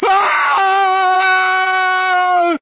scream5.ogg